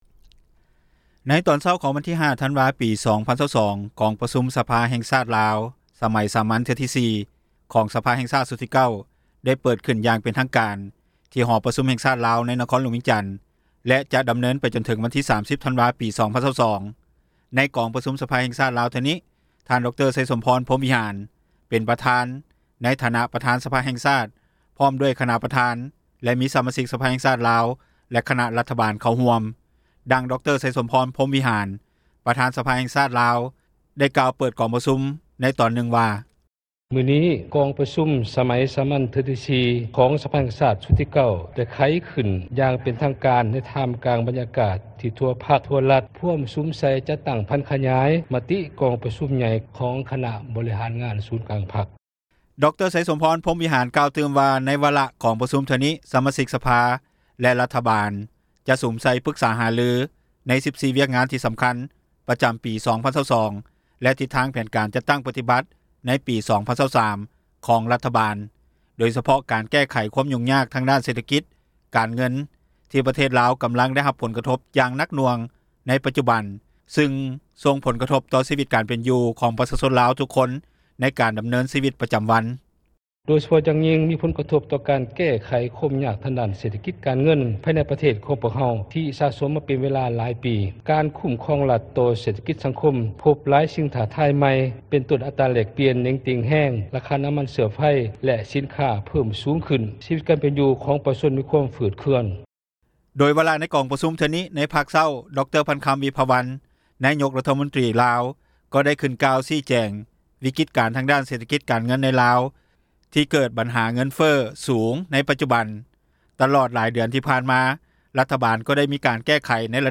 ດັ່ງຊາວລາວ ໃນແຊວງຫລວງພຣະບາງ ທ່ານນຶ່ງກ່າວວ່າ: